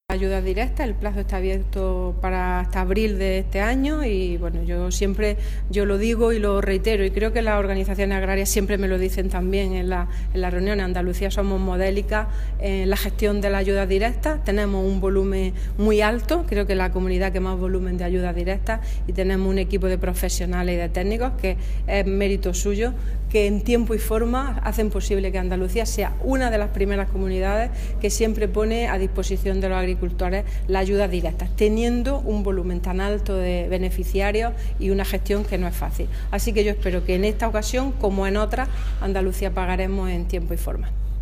Declaraciones consejera ayudas PAC